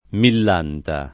mill#nta] num.